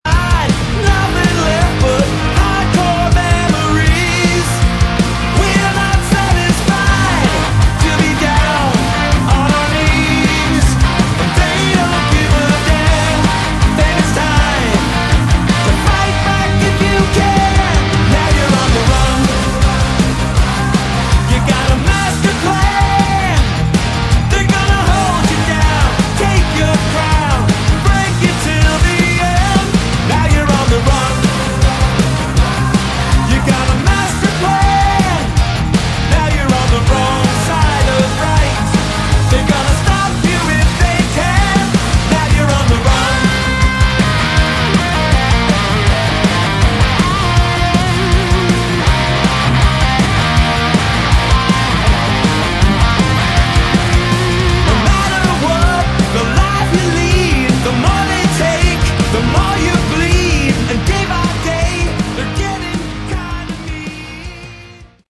Category: Hard Rock
lead and backing vocals
guitar, keys
bass, backing vocals